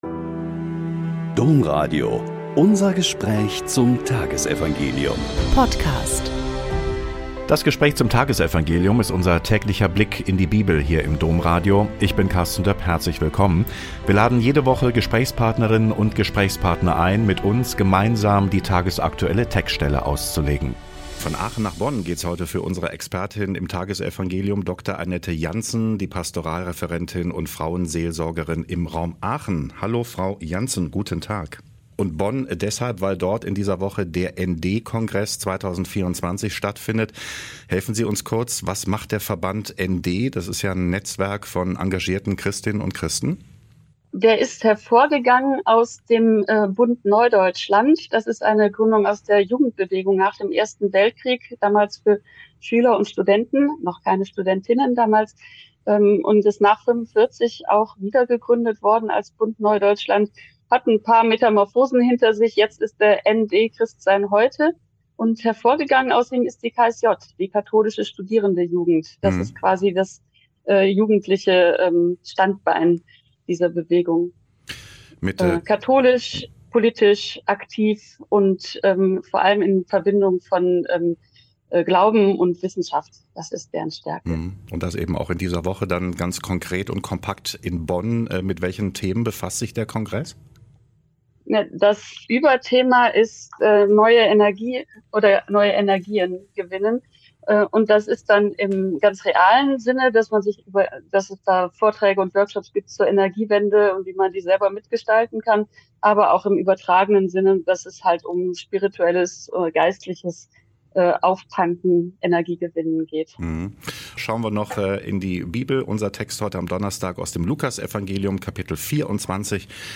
Lk 24,35-48 - Gespräch